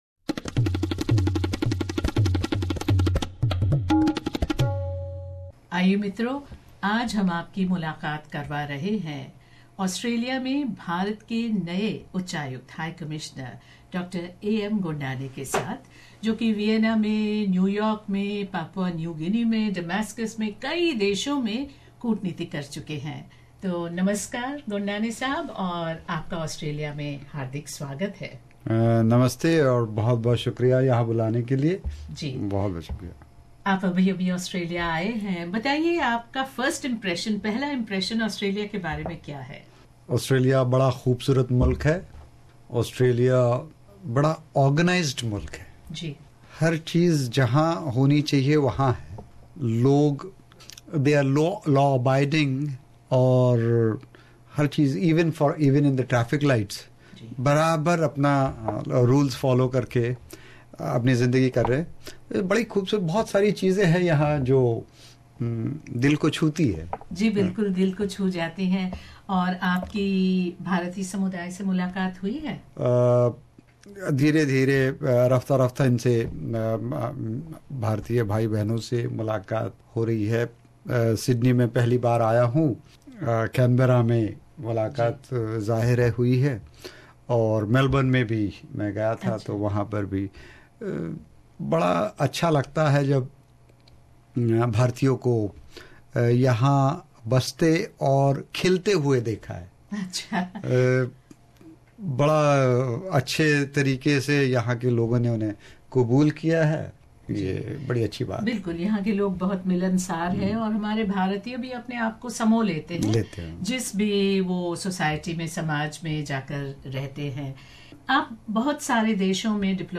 India's New High Commissioner to Australia Dr A.M. Gondane is ready to accelerate the positive trajectory of relations between India and Australia. In his first media interview after taking office the High Commissioner said he was most impressed with the organized and disciplined life in Australia.